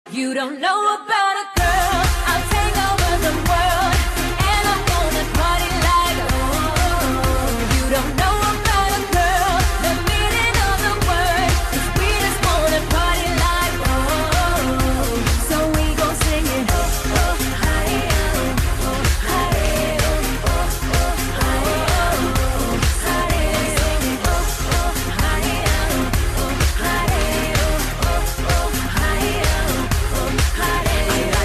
M4R铃声, MP3铃声, 欧美歌曲 297 3